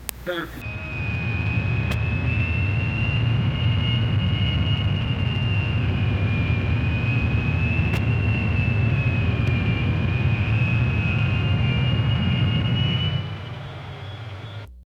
deep, pulsing drone of multiple propeller-driven bomber engines, high-pitched whine of fighter aircraft engines, staccato popping of distant anti-aircraft guns, whistling of shells passing through the air 0:15 Created Feb 26, 2025 2:12 AM
deep-pulsing-drone-of-mul-4zhnrxkk.wav